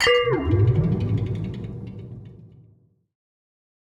Minecraft Version Minecraft Version latest Latest Release | Latest Snapshot latest / assets / minecraft / sounds / block / respawn_anchor / charge2.ogg Compare With Compare With Latest Release | Latest Snapshot
charge2.ogg